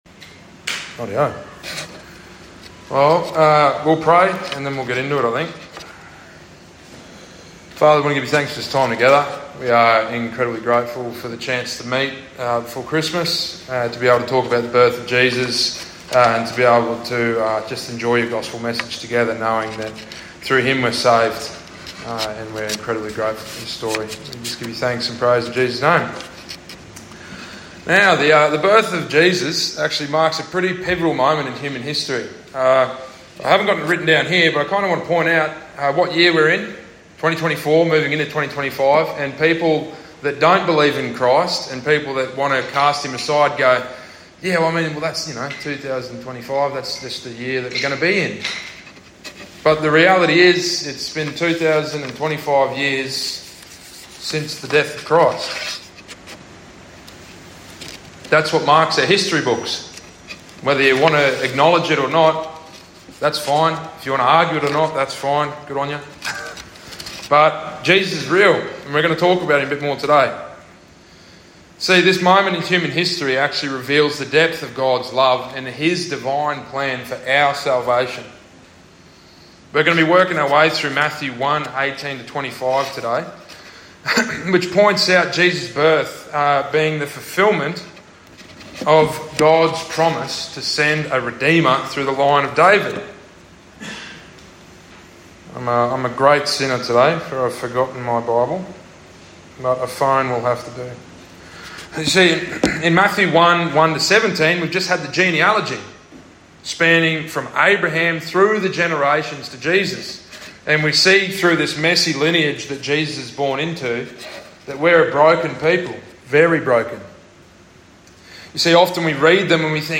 Christmas Service